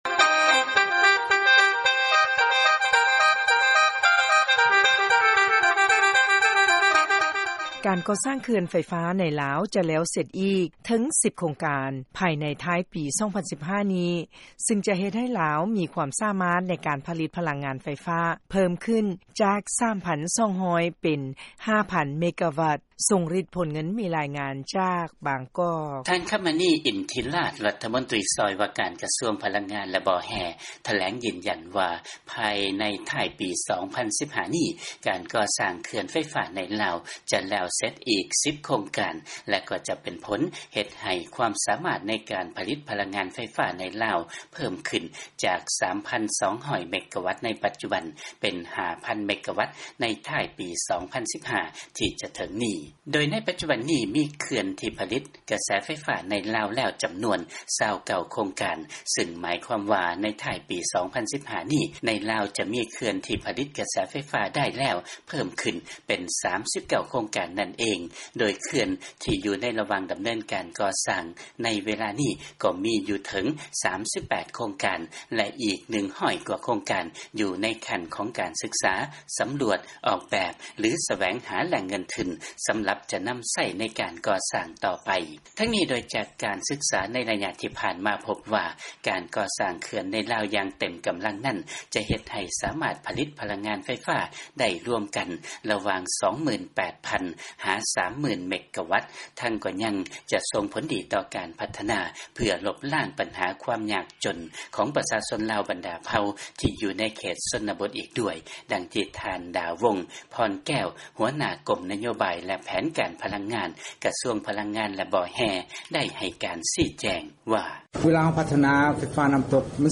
ຟັງລາຍງານ 10 ໂຄງການກໍ່ສ້າງ ເຂື່ອນໄຟຟ້າ ໃນລາວ ຈະແລ້ວສຳເລັດ ພາຍໃນທ້າຍປີ 2015.